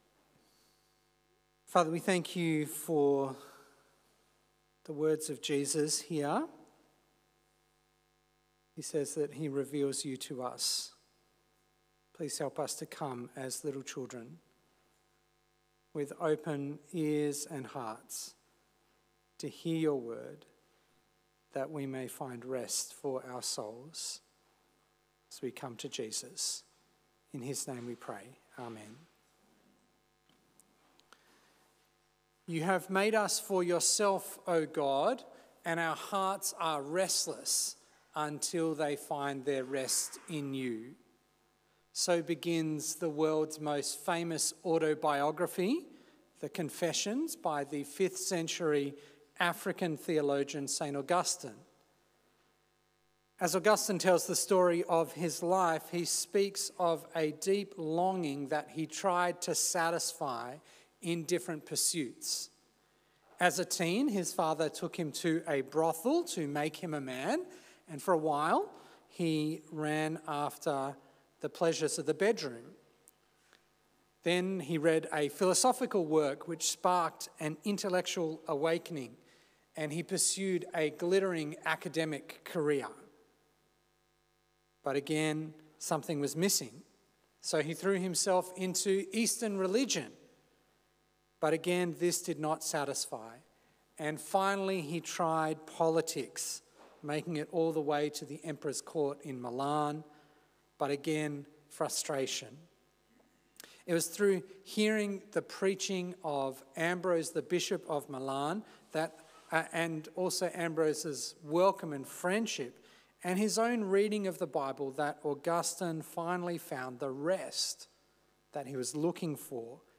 A sermon on Matthew 11